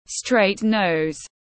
Mũi thẳng tiếng anh gọi là straight nose, phiên âm tiếng anh đọc là /streɪt nəʊz/ .
Straight nose /streɪt nəʊz/